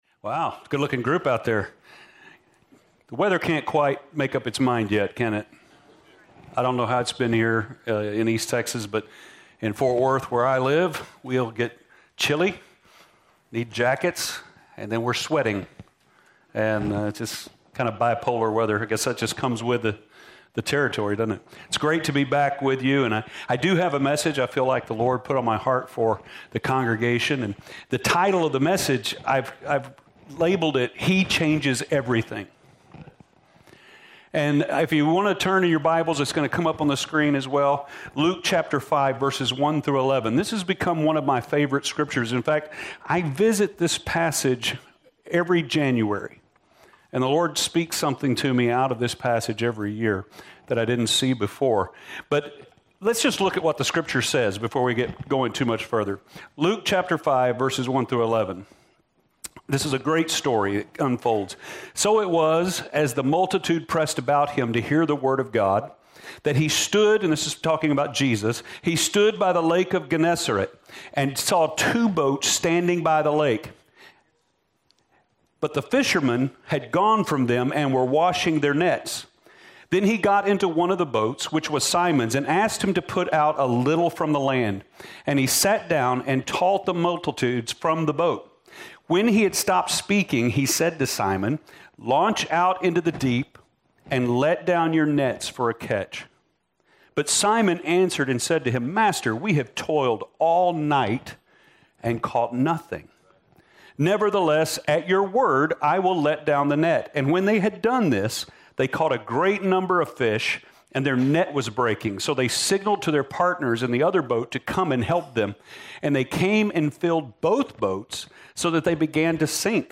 2018 Sermon